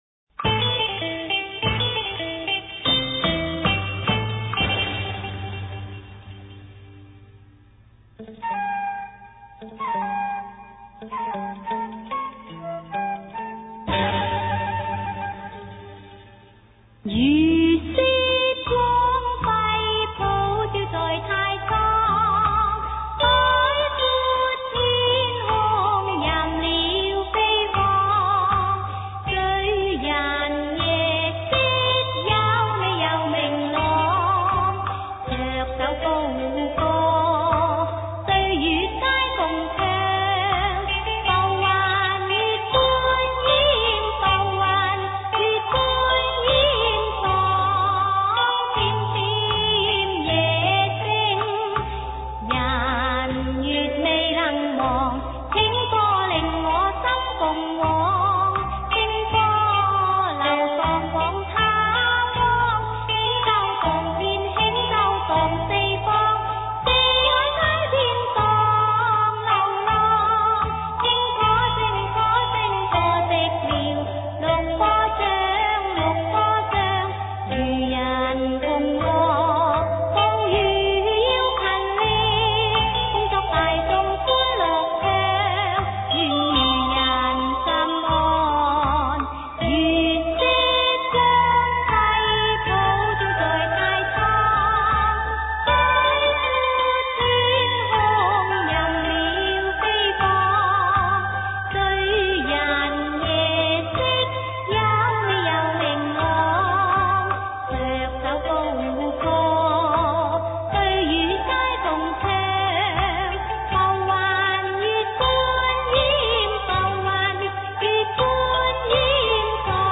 此曲的開始和結尾主旋律完全相同，最後一句漸漸收慢，是廣東音樂傳統習慣。